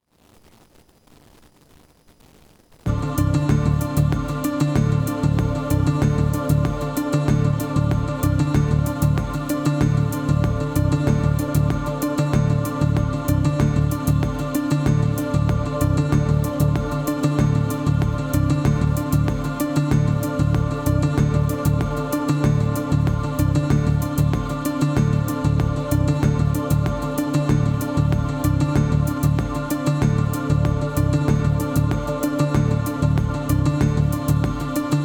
Anyway, here is the video you have asked for, where the Android phone is connected to the CC3200AUDBOOST line-in, and the line-out is connected to the computer microphone input which is recording the audio with Audacity: